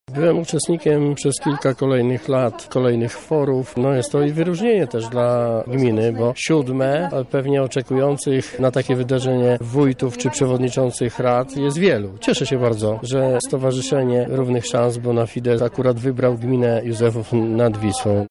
„Odczuwamy ogromną satysfakcję, że siódme forum odbywa się akurat w Józefowie nad Wisłą” – mówi wójt gminy Józefów Grzegorz Kapica